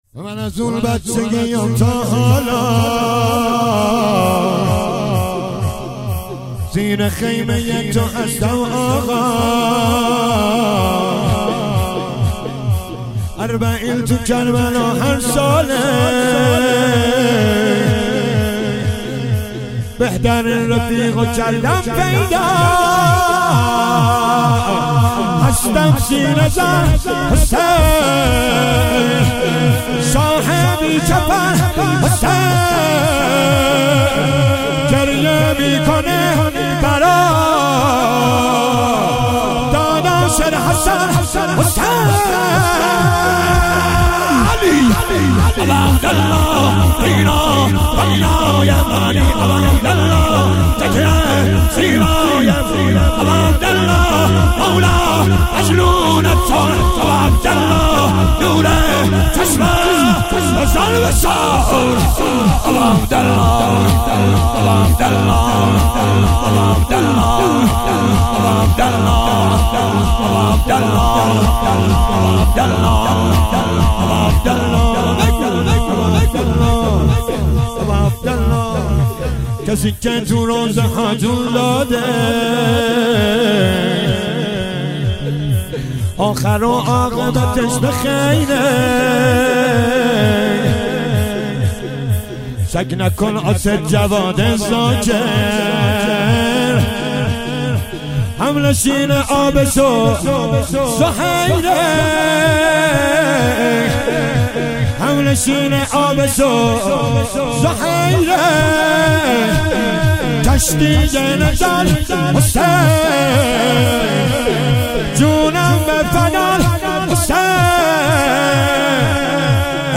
مداحی
محرم 1399 هیئت محبان الرقیه(س) شهر ری